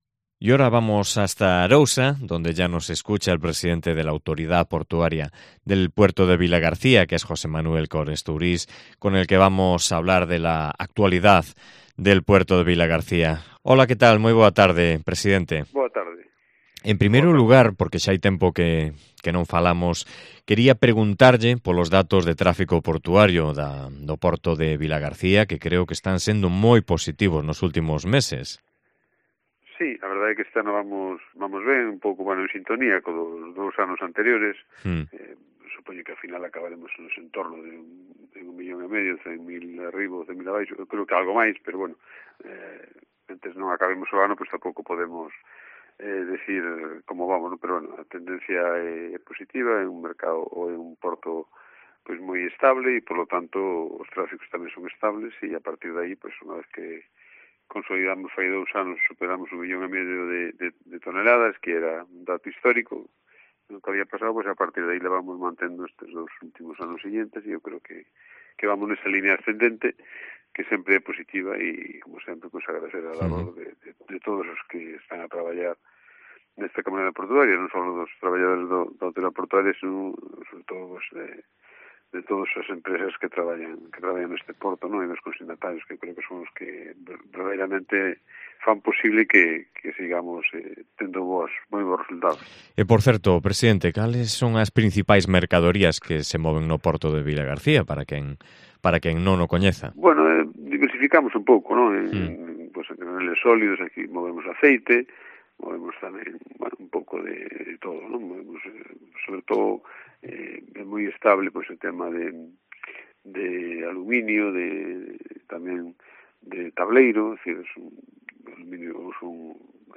AUDIO: Entrevista con José Manuel Cores Tourís, presidente de la Autoridad Portuaria de Vilagarcía